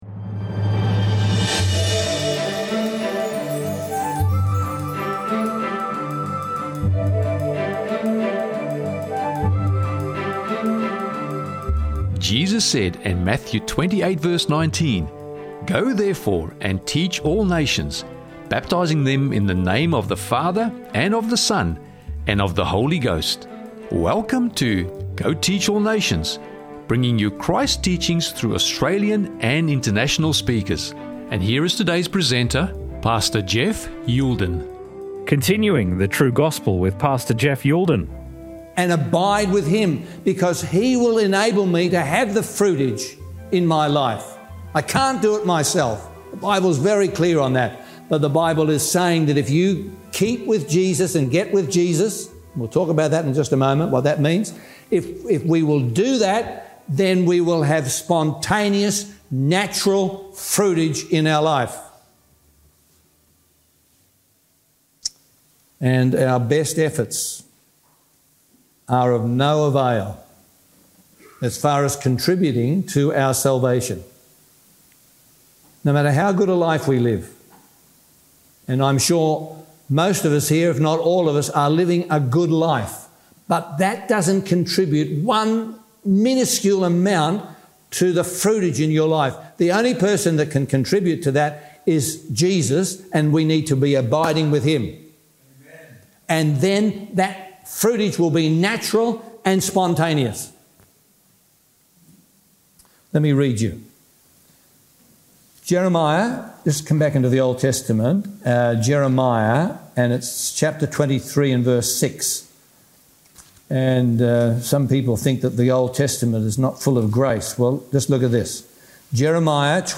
Living the True Gospel– Sermon Audio 2609